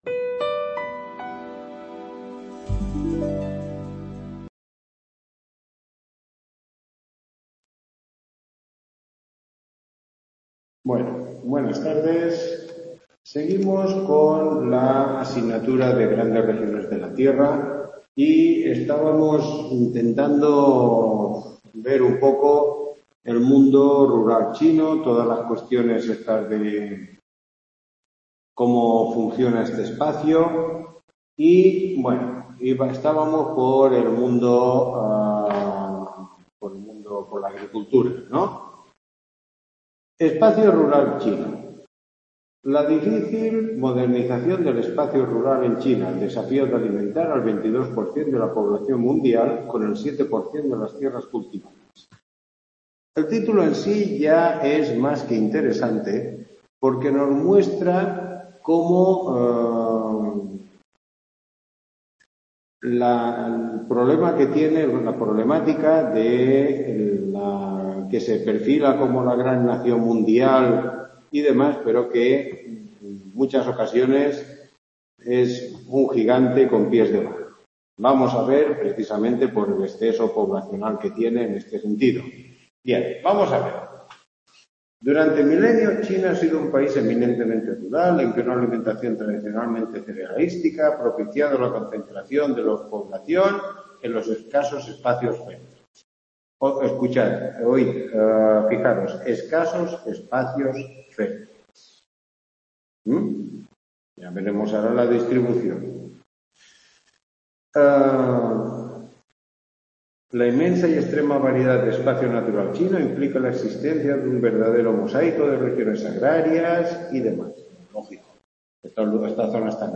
Recuperación tutoría